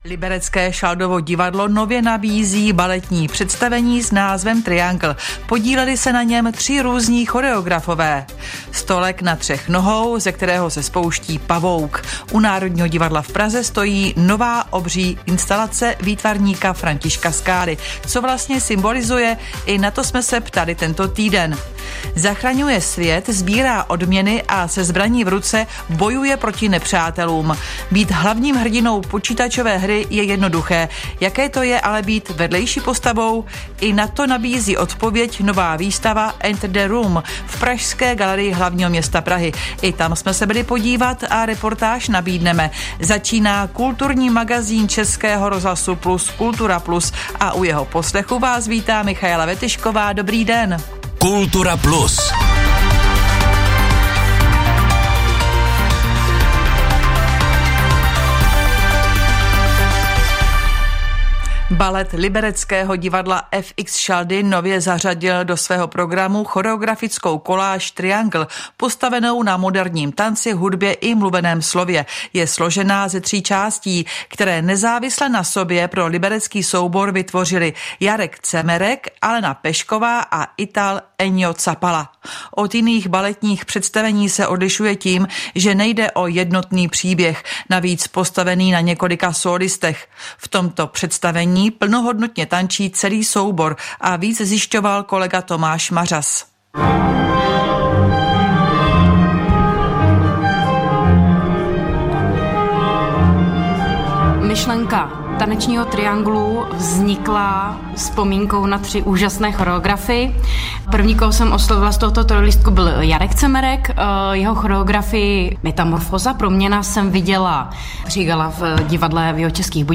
Hlavní zprávy - rozhovory a komentáře: O všem, co se právě děje - 05.04.2025